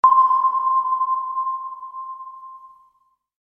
sonar-ping_24906.mp3